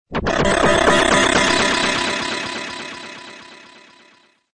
Descarga de Sonidos mp3 Gratis: electronica 1.